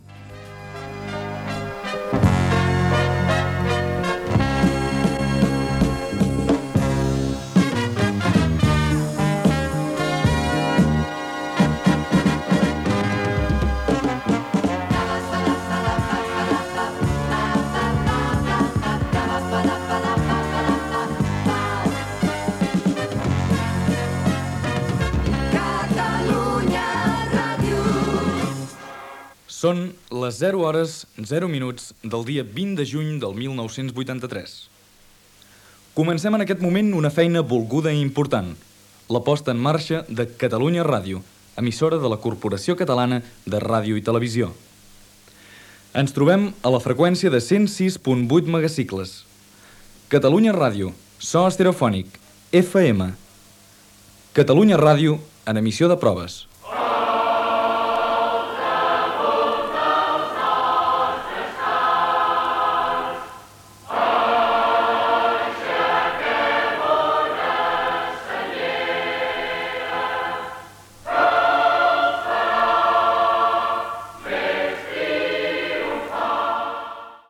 Sintonia de l'emissora, primeres paraules en l'emissió inaugural en proves: hora, data i identificació. "El cant de la senyera"
FM